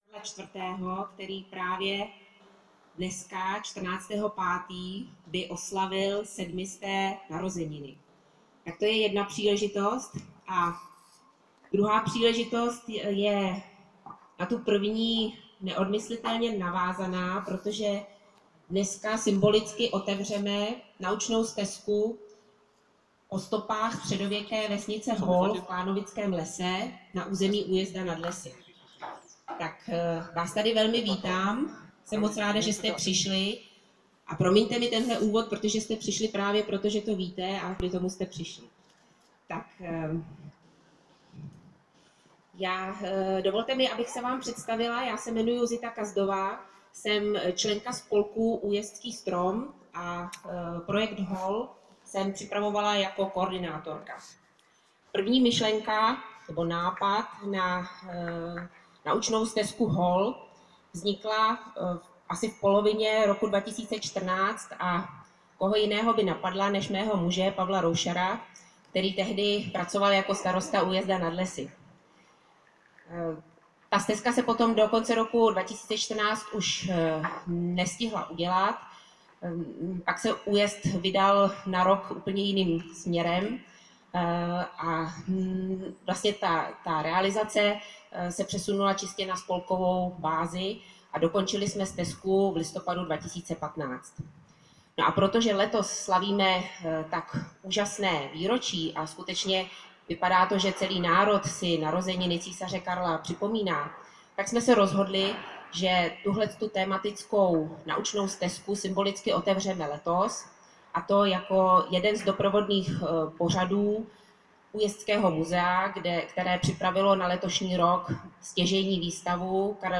Přivítání a úvod k slavnostnímu otevření naučné stezky HOL: